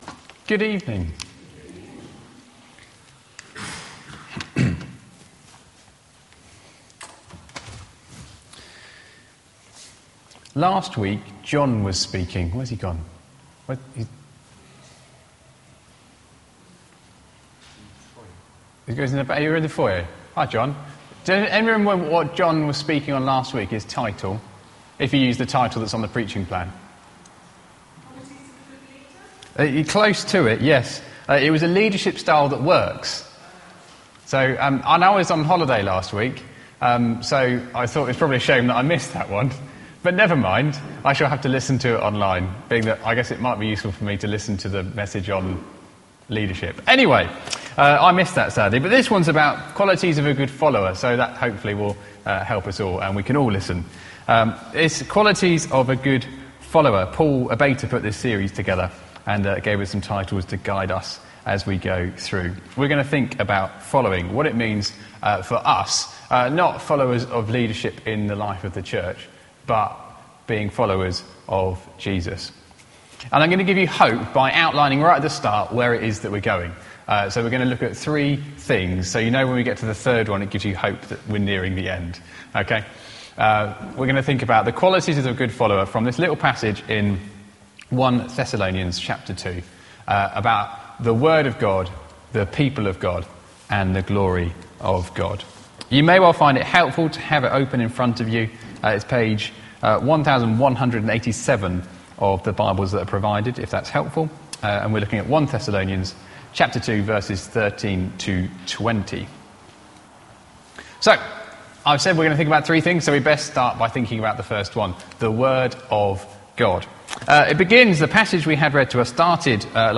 Media for Worship Service on Sun 30th Oct 2016 18:30